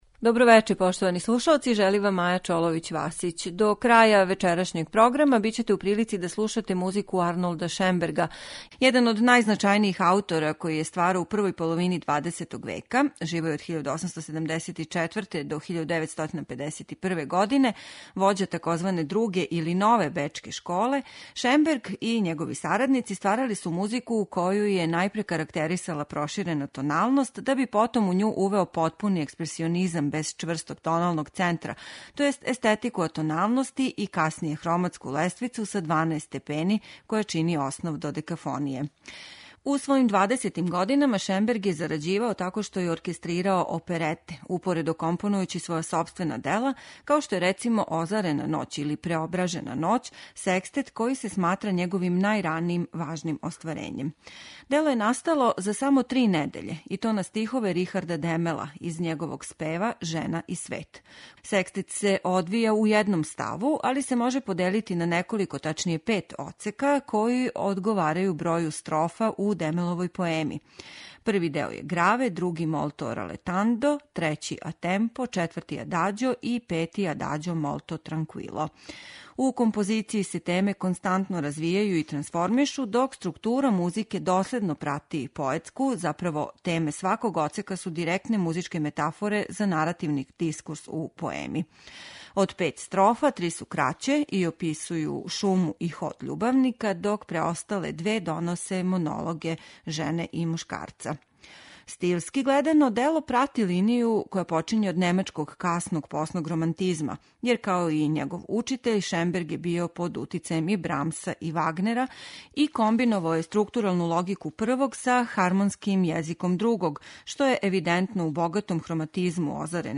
секстета